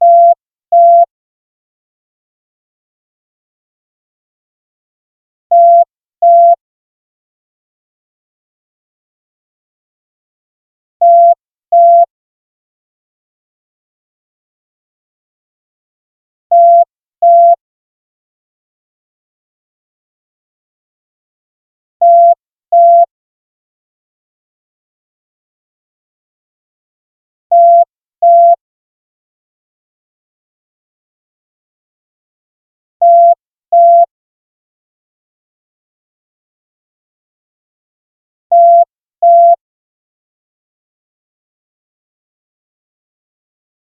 Sync it with your cell phone and 1) an incoming call is announced with the classic "boop-boop" tone, then 2) the opening changes to the second-most common four-pulse chirp.
Communicator Hailing Beeps.mp3